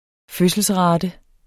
Udtale [ ˈføsəls- ]